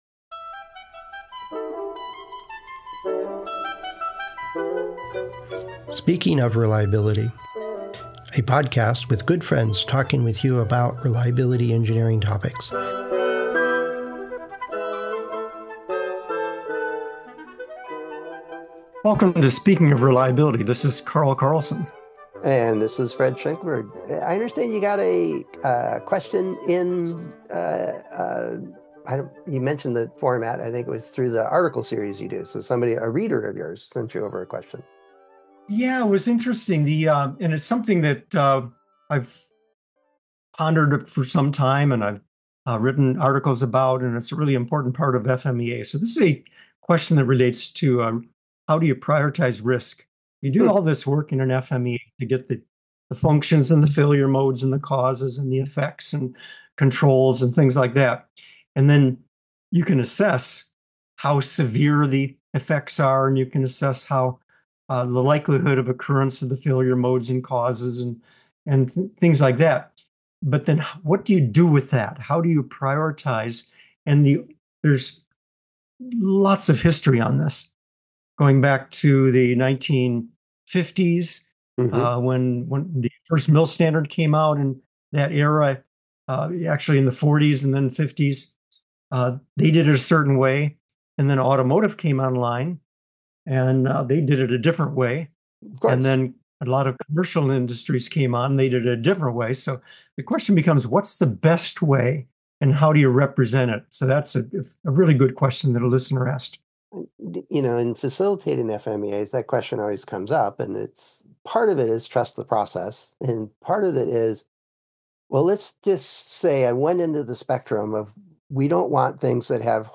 Speaking Of Reliability: Friends Discussing Reliability Engineering Topics